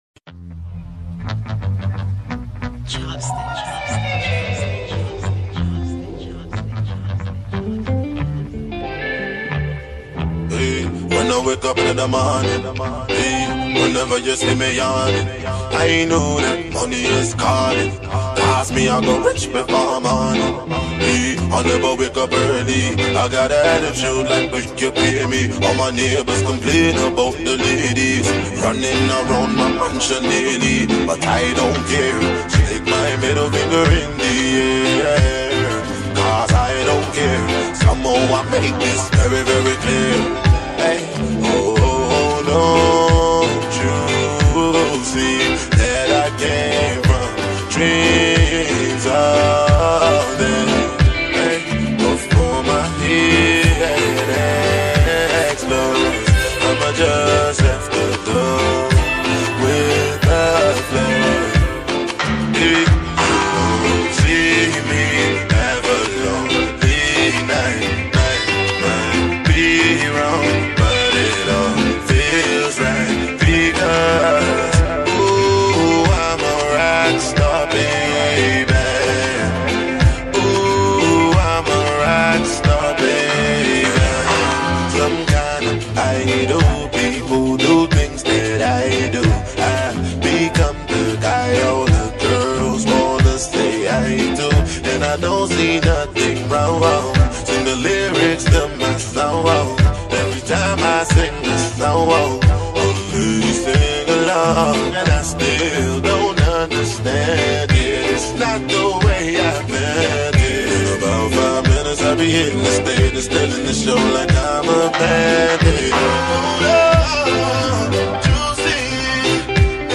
Afro-dancehall